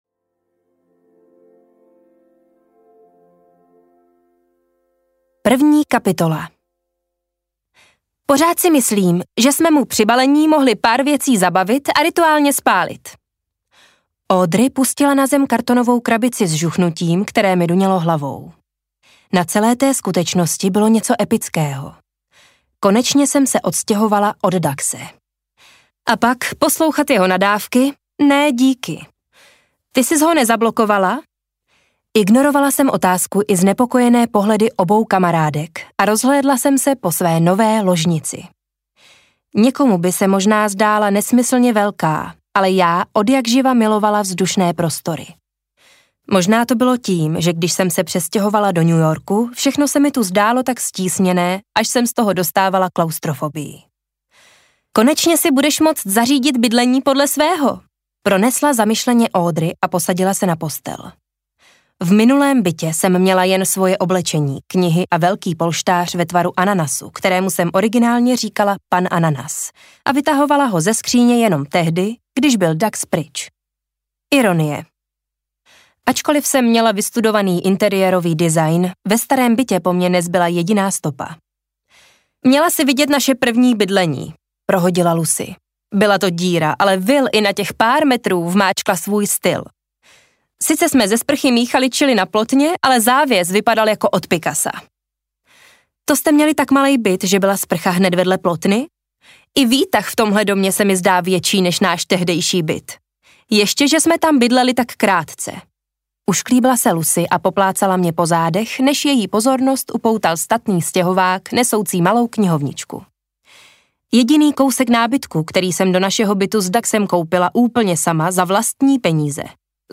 Zakázané doteky audiokniha
Ukázka z knihy